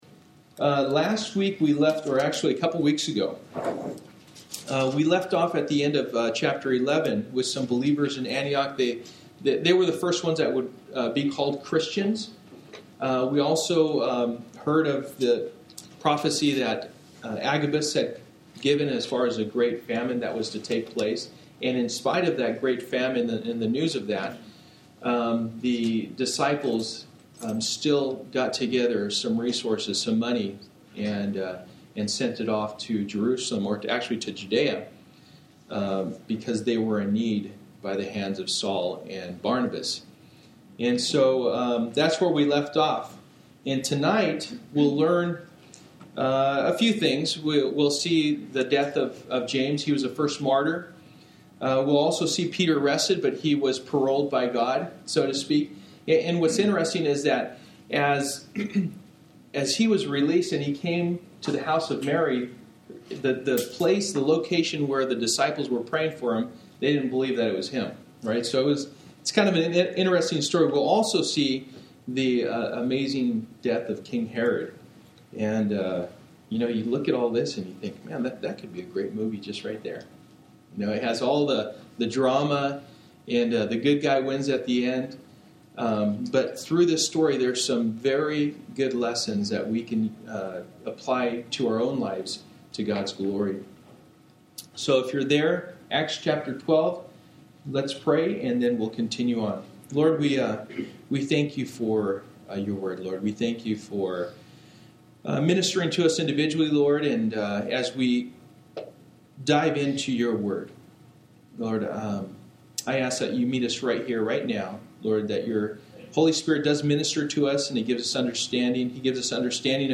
Passage: Acts 12:1-25 Service: Wednesday Night